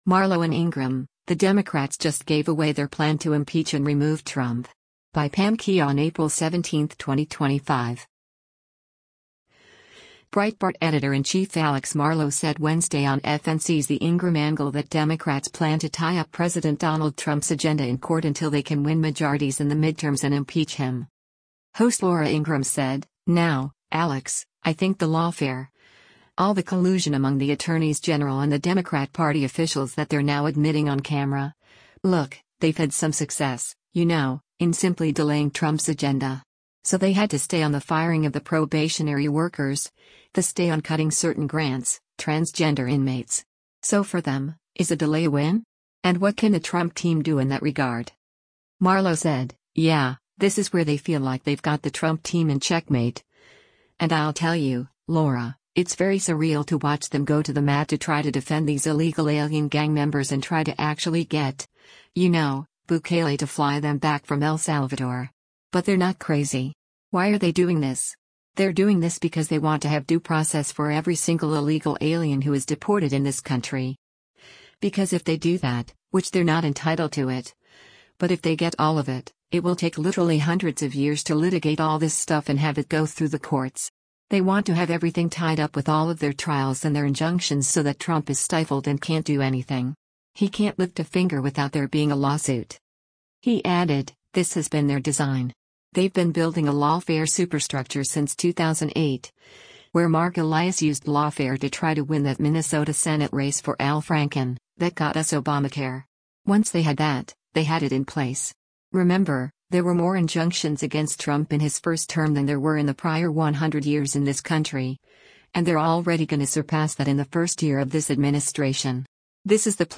Breitbart Editor-in-Chief Alex Marlow said Wednesday on FNC’s “The Ingraham Angle” that Democrats plan to tie up President Donald Trump’s agenda in court until they can win majorities in the midterms and impeach him.